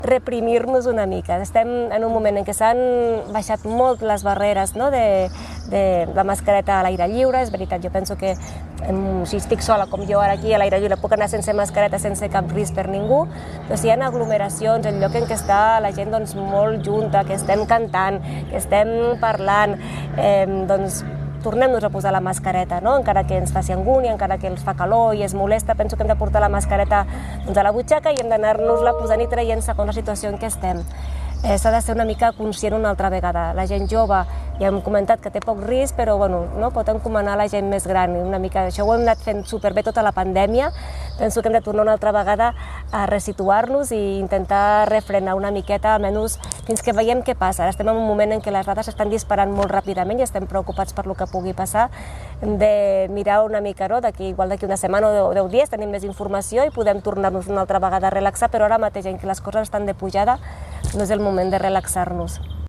Declaracions